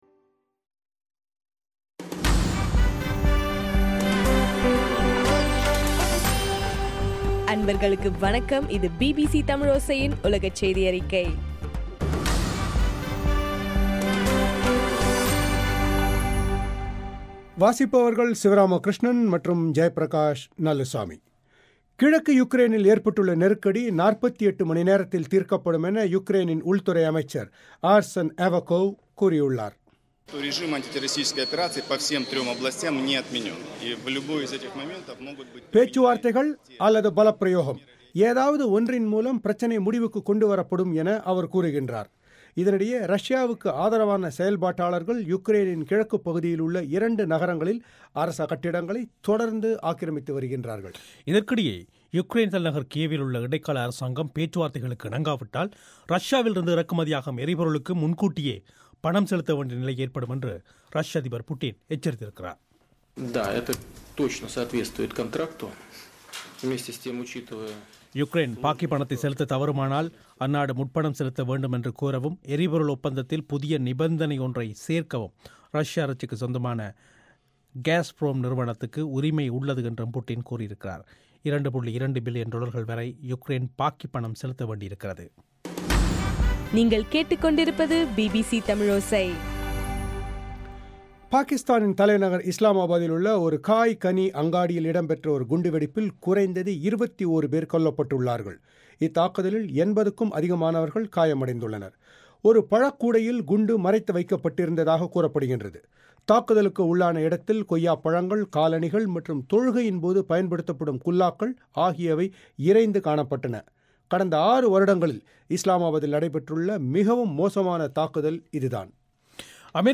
ஏப்ரல் 9 2014 பிபிசி தமிழோசையின் உலகச் செய்திகள்